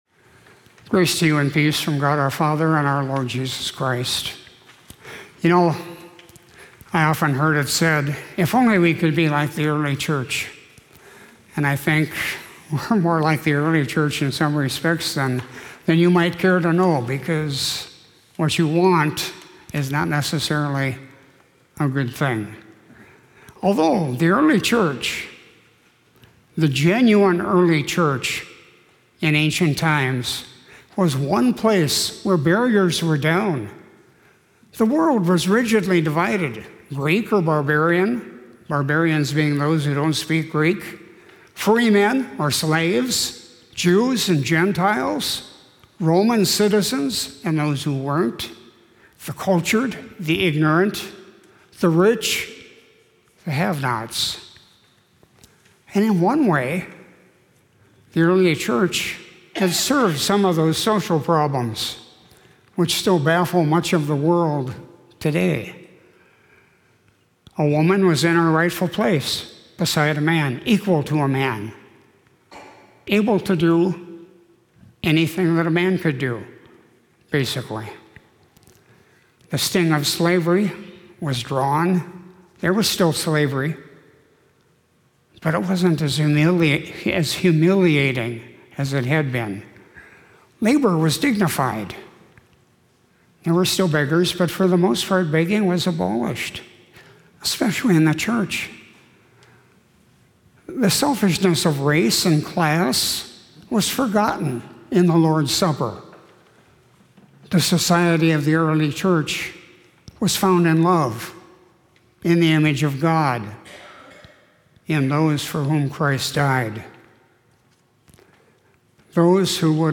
Sermon for Thursday, April 6, 2023